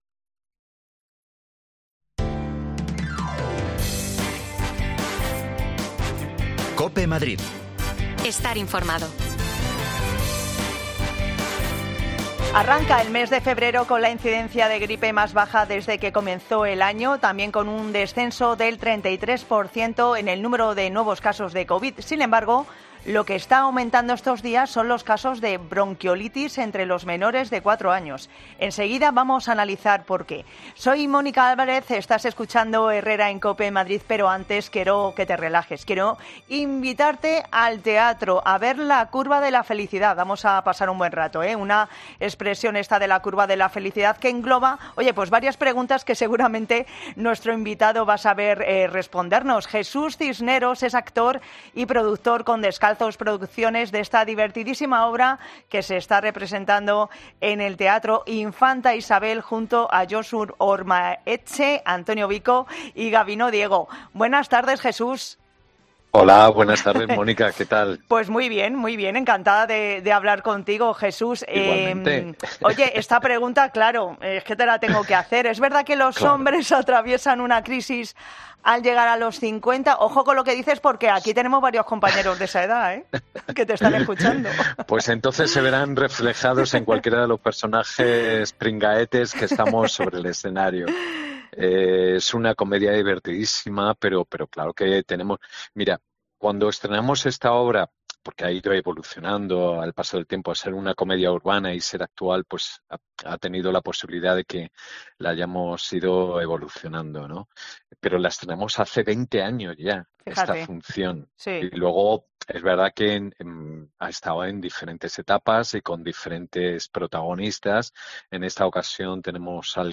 Bajan los casos de gripe y de covid pero suben los de bronquiolitis, especialmente entre los menores. Hablamos con los expertos para saber cúal es la razón
Las desconexiones locales de Madrid son espacios de 10 minutos de duración que se emiten en COPE, de lunes a viernes.